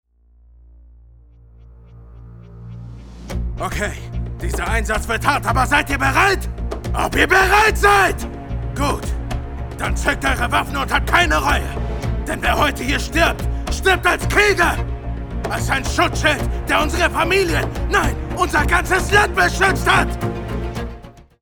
German Voice Over Artist
Sprechprobe: eLearning (Muttersprache):